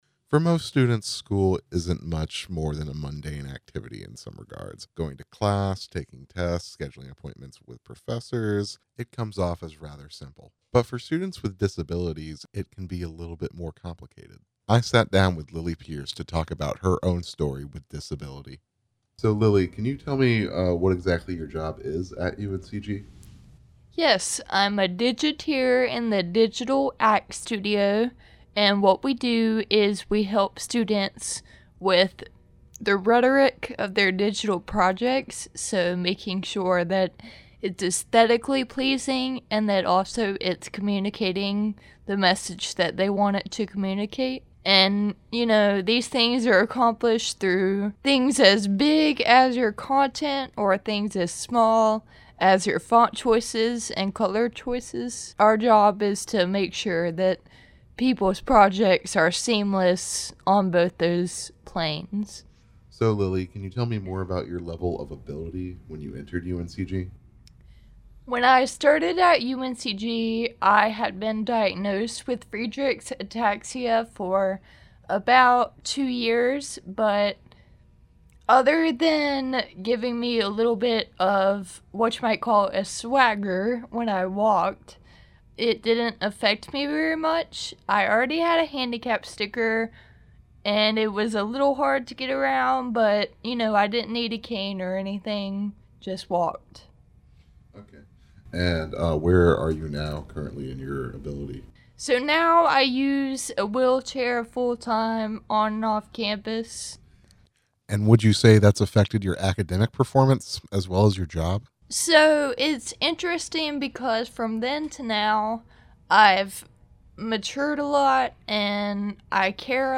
comes by studio to discuss the current state of accessibility on campus.